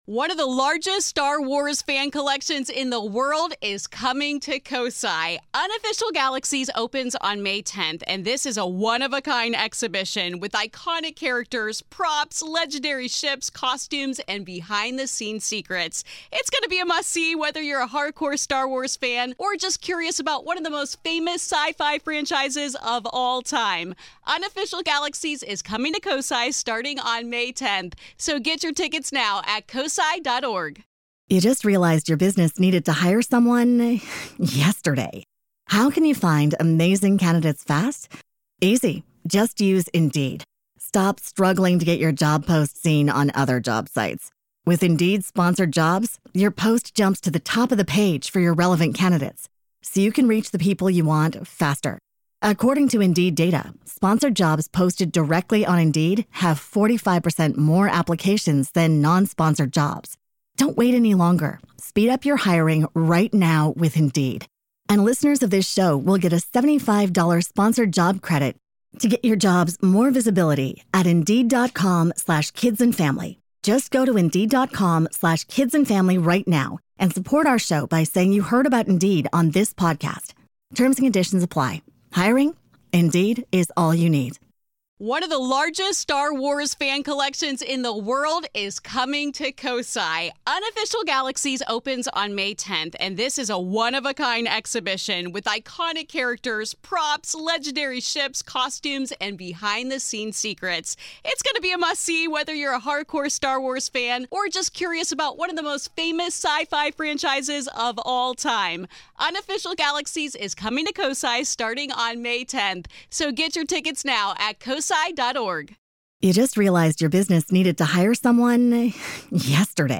Today, we discuss her research, experiences, and the beliefs she’s come to hold on her journey through the paranormal. This is Part Two of our conversation.